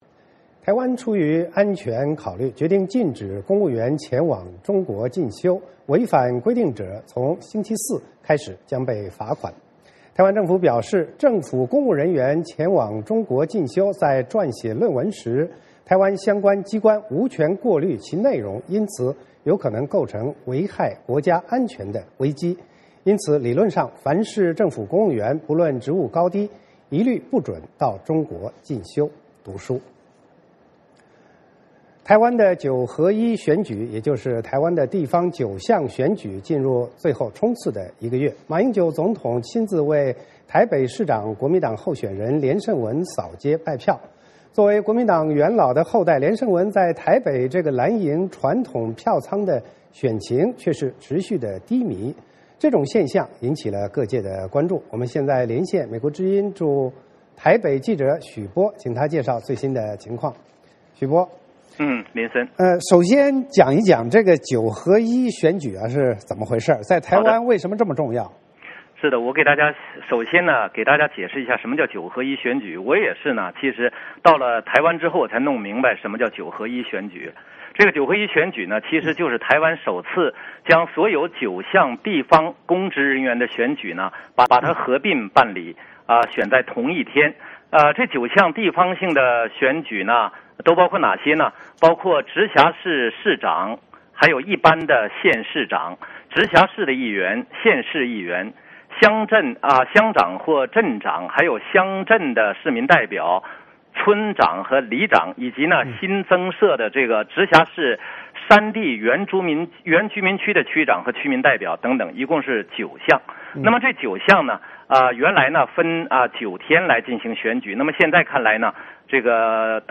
VOA连线：台湾选举：蓝营传统票仓选情低迷